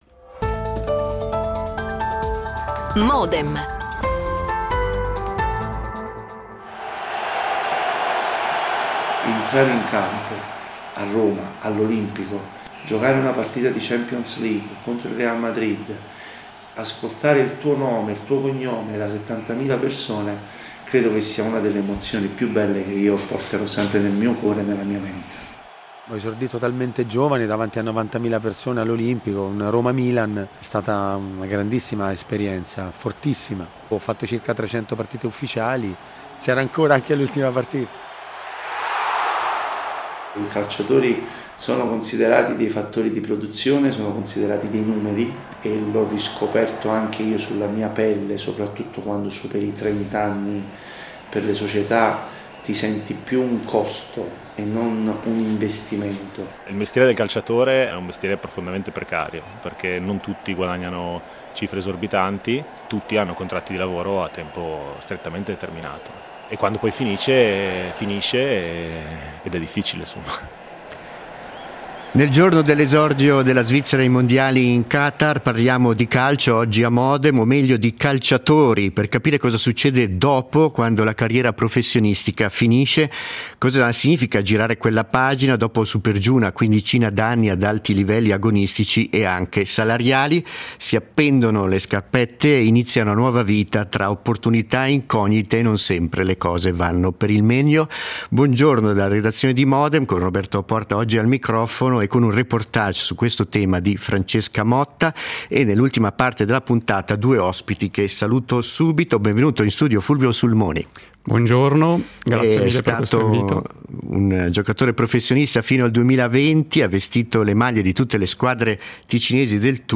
Dopo il reportage, spazio alla discussione con due ospiti:
L'attualità approfondita, in diretta, tutte le mattine, da lunedì a venerdì